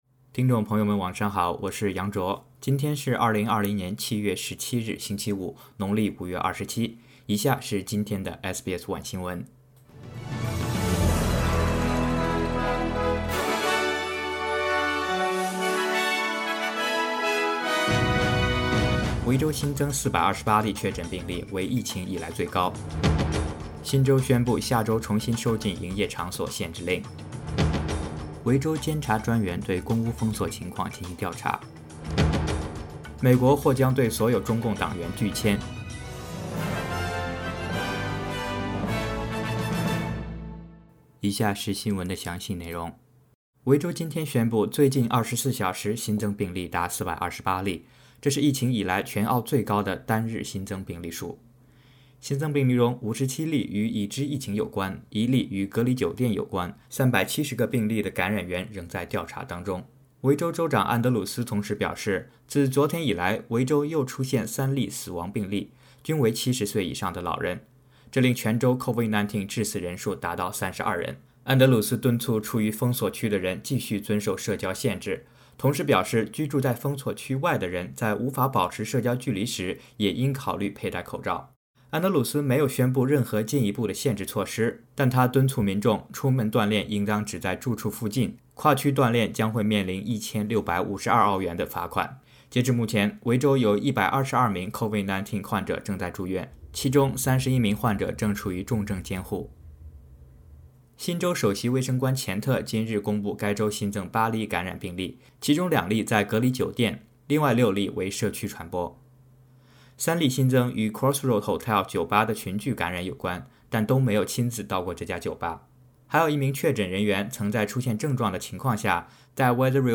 SBS晚新闻（7月17日）